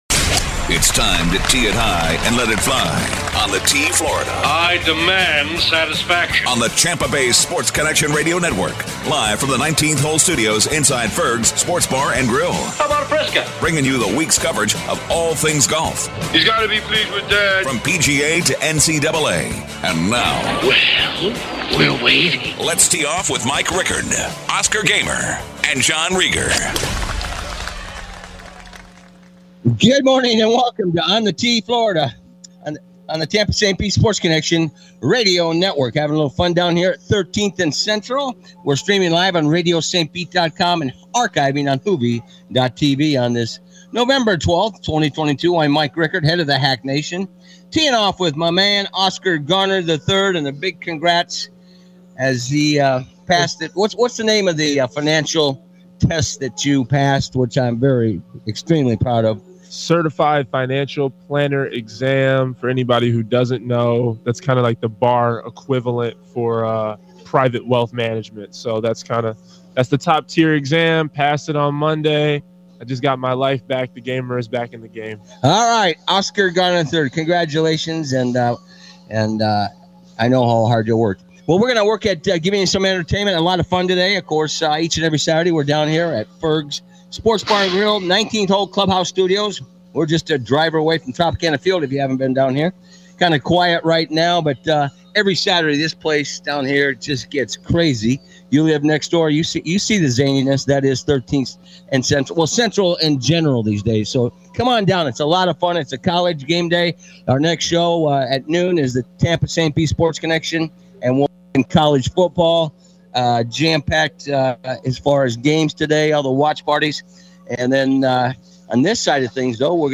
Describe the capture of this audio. Live from Ferg's 11-12-22